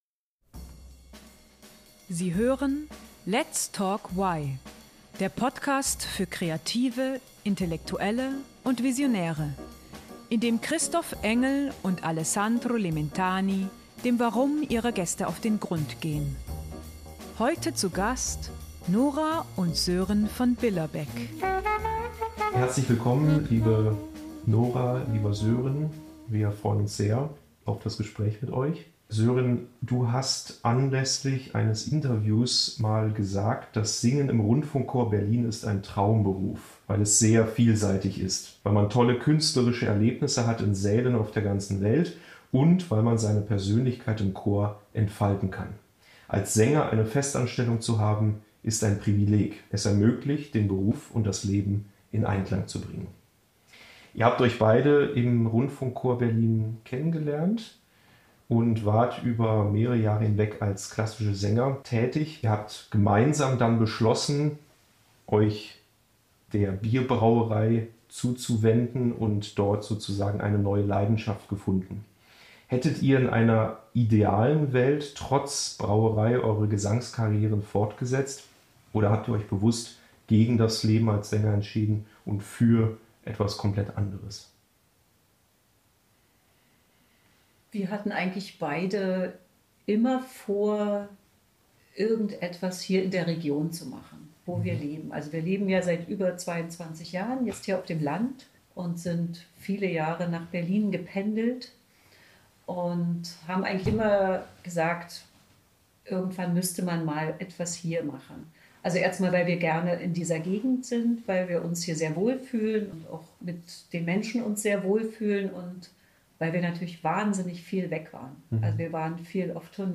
Erfahrt in dieser Folge, wie der Weg von der Musik zur Braukunst verlief, welche Werte hinter dem Barnimer Brauhaus stehen und was das perfekte Bier ausmacht. Das Interview wurde am 14. Juni 2024 aufgezeichnet.